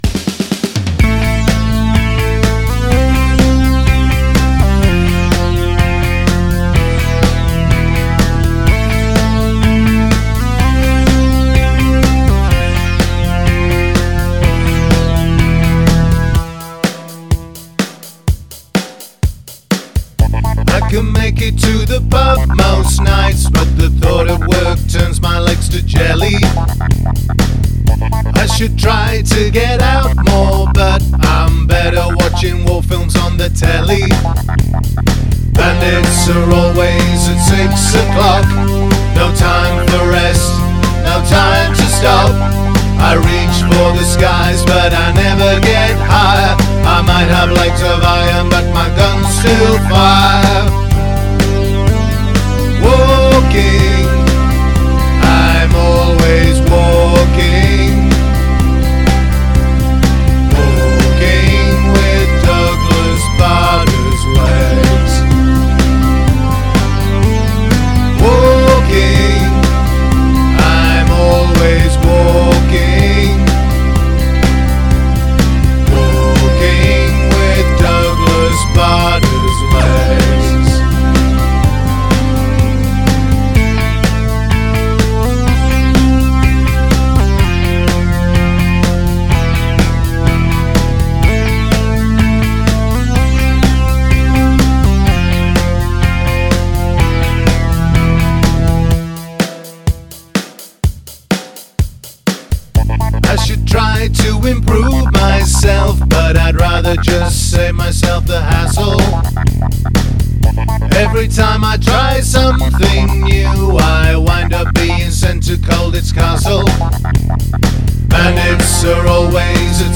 Rough Mix